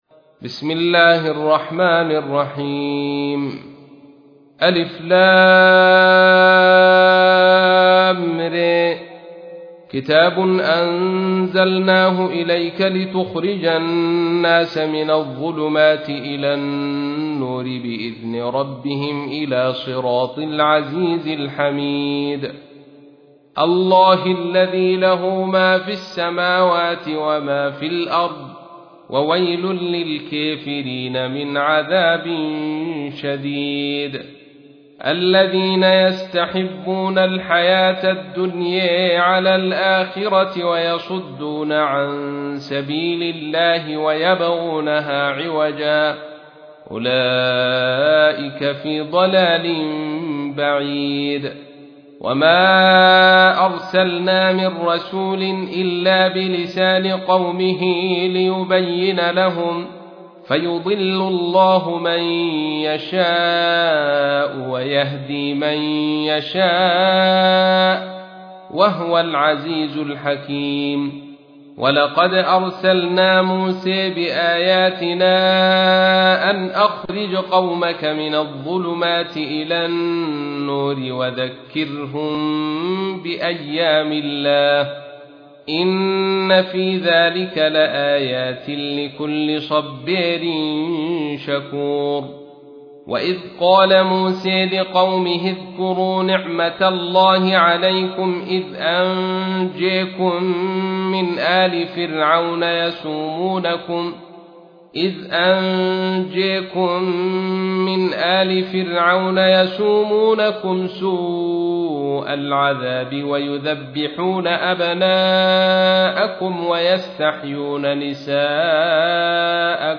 تحميل : 14. سورة إبراهيم / القارئ عبد الرشيد صوفي / القرآن الكريم / موقع يا حسين